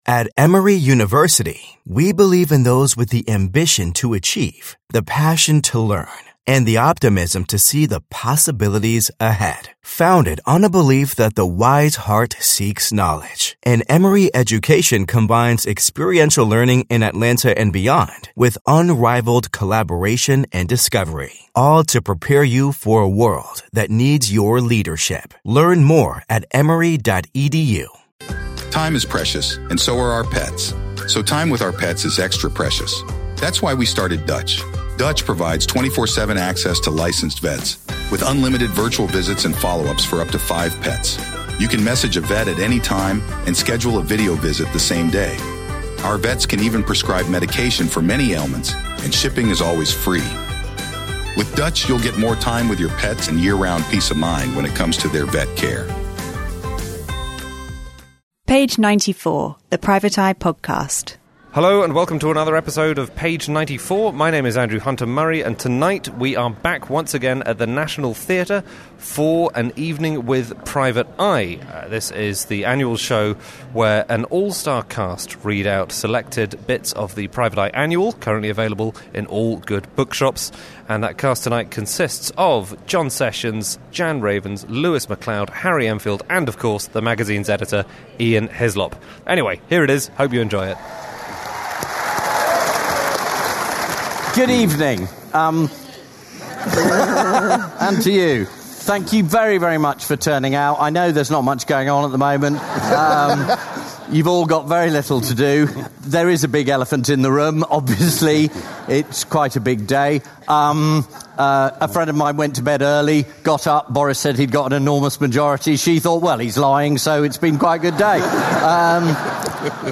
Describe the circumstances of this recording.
48: Live at the National Theatre!